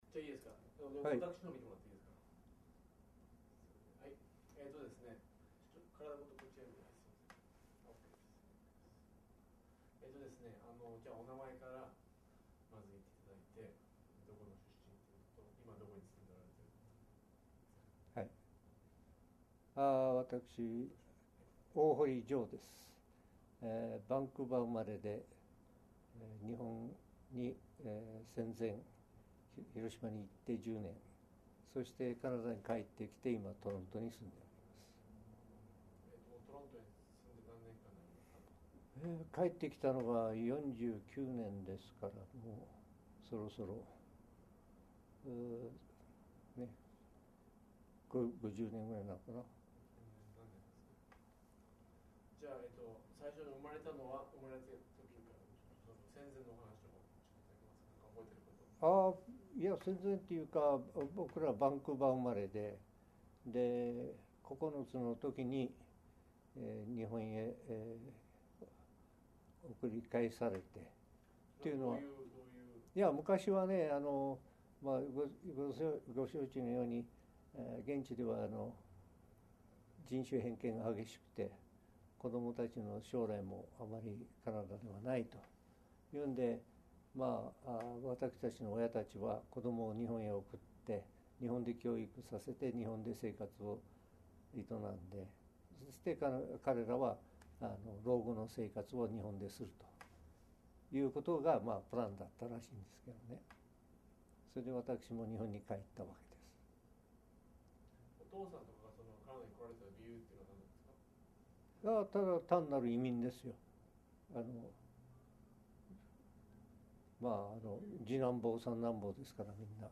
oral history interviews